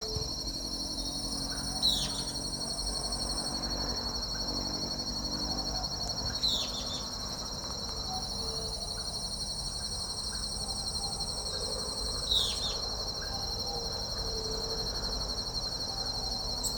Mosqueta Parda (Lathrotriccus euleri)
Nombre en inglés: Euler´s Flycatcher
Localidad o área protegida: Concordia
Condición: Silvestre
Certeza: Observada, Vocalización Grabada